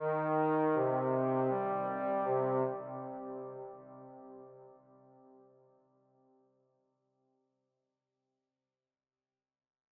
bass_champ_arpeggio.wav